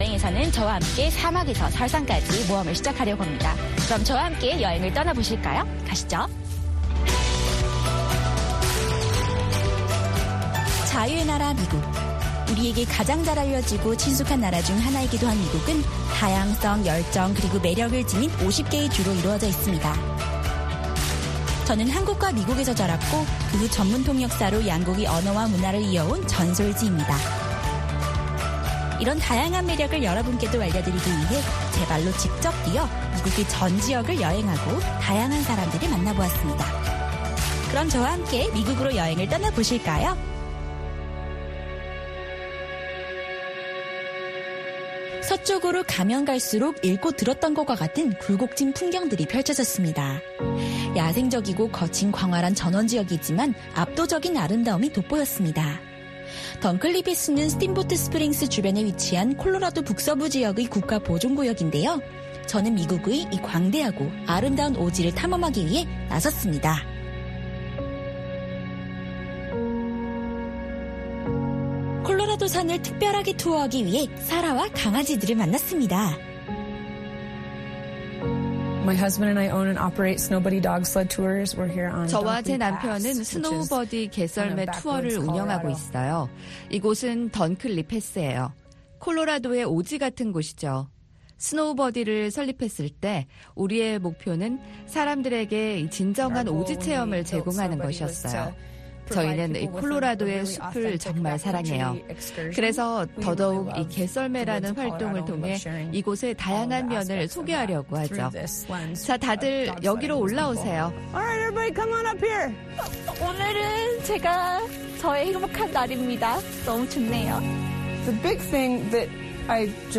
VOA 한국어 방송의 일요일 오전 프로그램 2부입니다. 한반도 시간 오전 5:00 부터 6:00 까지 방송됩니다.